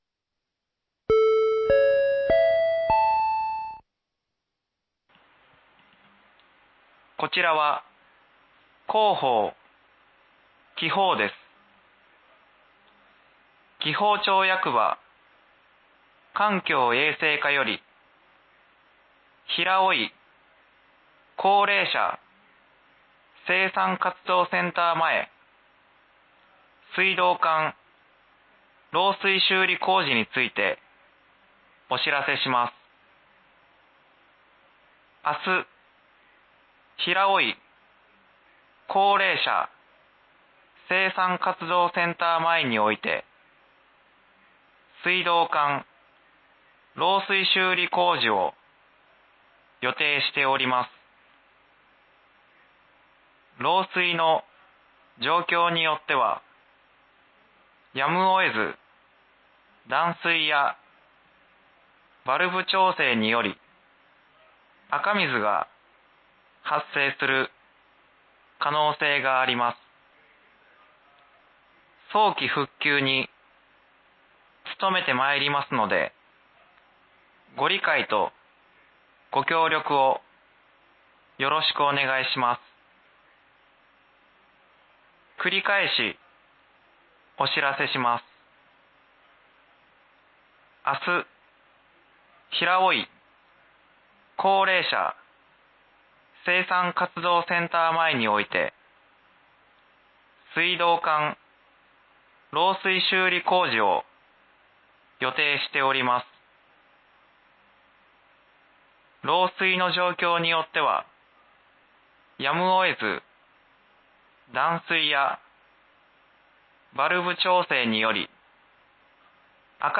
（平尾井地区のみの放送です）
放送音声